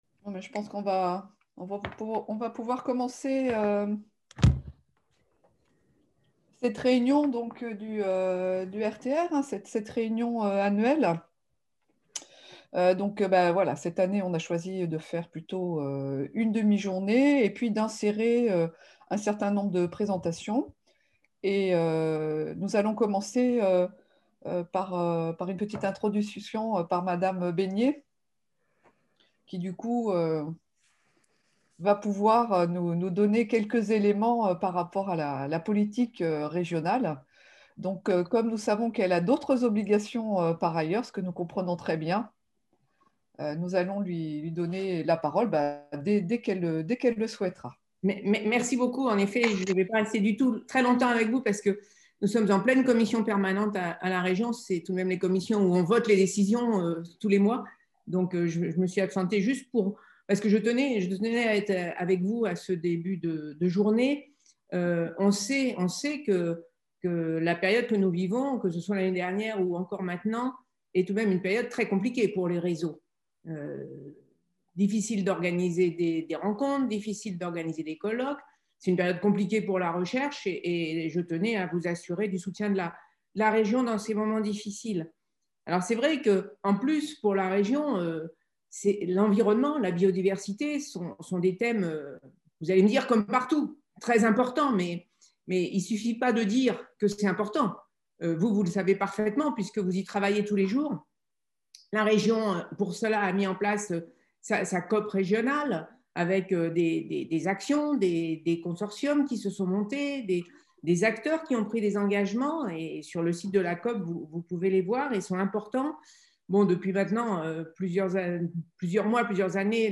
Discours d'Anne Besnier, vice-présidente déléguée à l’Enseignement Supérieur et de la Recherche | Canal U
Discours d'ouverture de la journée annuelle du RTR MiDi Anne Besnier, vice-présidente déléguée à l’Enseignement Supérieur et de la Recherche - Région Centre-Val de Loire Programme à télécharger dans l'onglet document ci-dessus